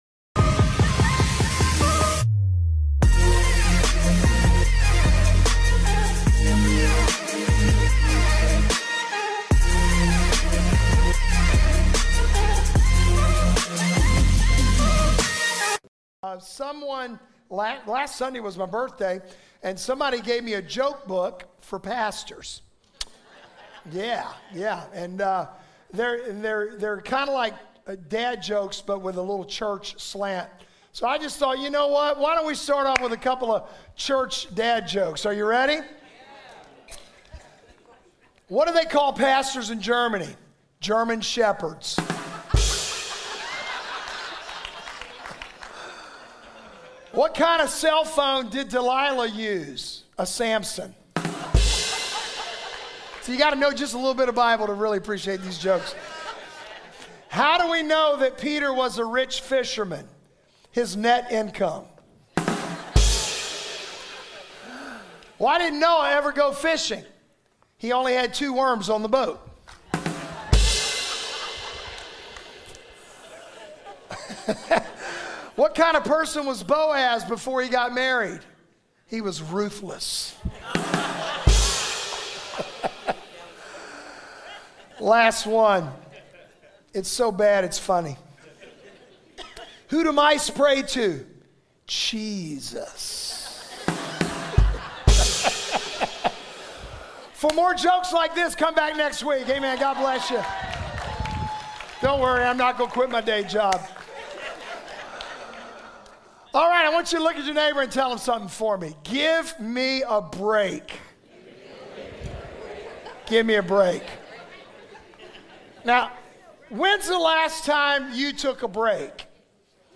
The sermon uses the analogy of an overloaded backpack to illustrate how we often try to carry too many burdens on our own.